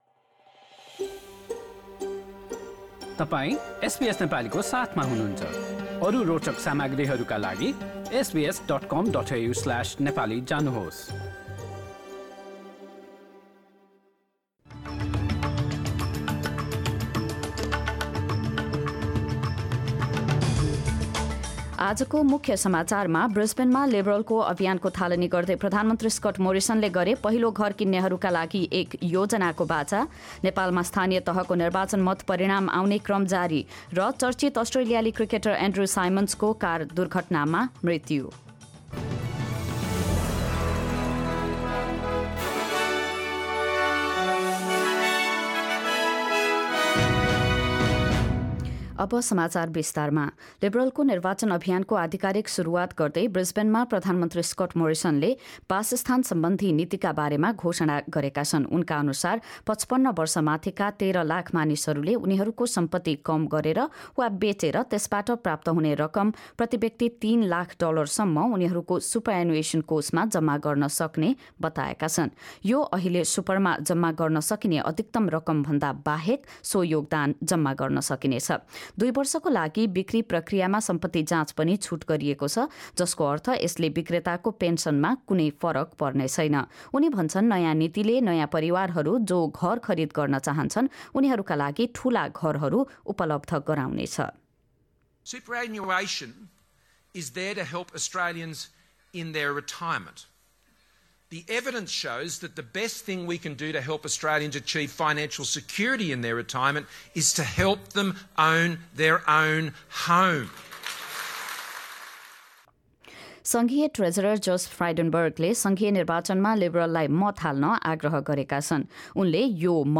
Listen to the latest news headlines from Australia in Nepali. In this bulletin; Prime Minister Scott Morrison pledges a first home buyer scheme as the Liberal Party launches its election campaign in Brisbane. A gunman kills 10 people in a 'racially motivated' attack live-streamed in a U-S supermarket and World cricket mourns the shock loss of Test star Andrew Symonds.